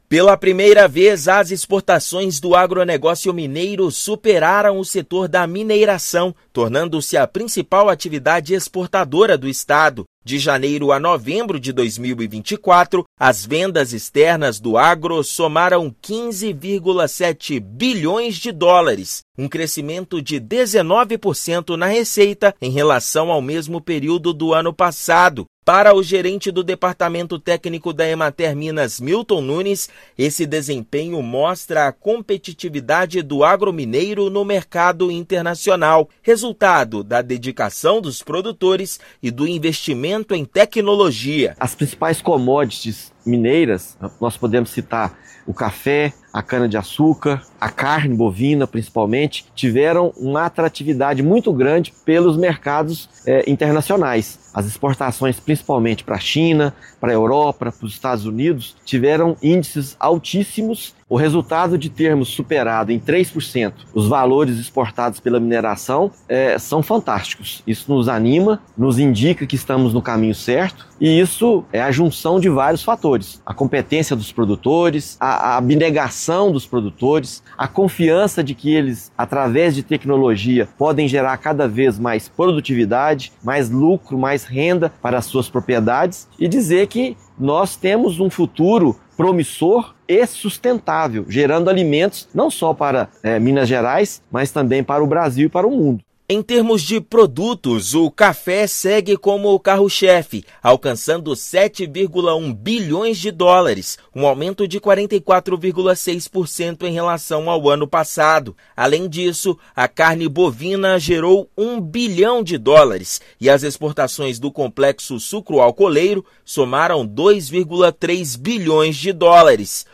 As vendas externas dos produtos agropecuários somaram US$ 15,7 bilhões, superando em 3% o valor alcançado pela mineração. Ouça matéria de rádio.